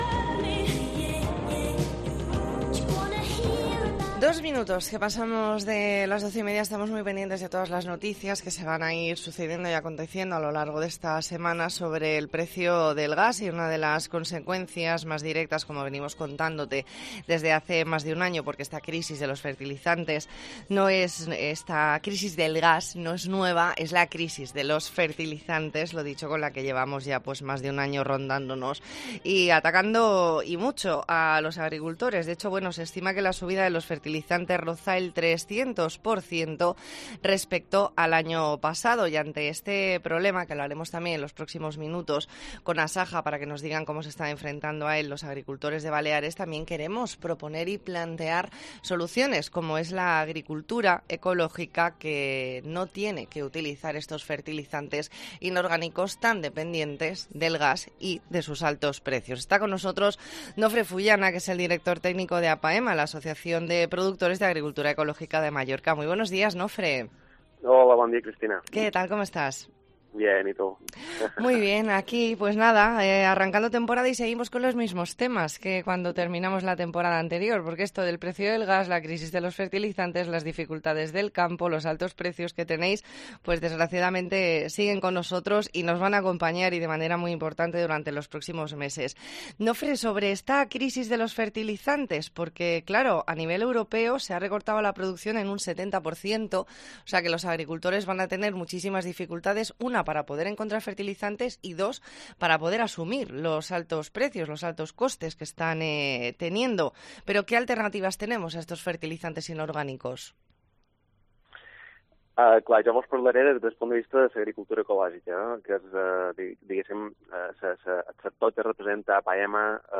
E ntrevista en La Mañana en COPE Más Mallorca, lunes 5 de septiembre de 2022.